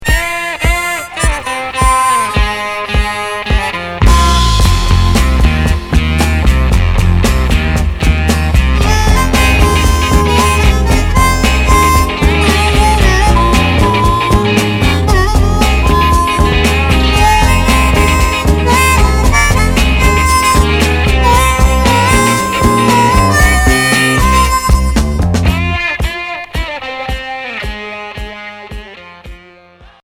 instrumental
Rock 70's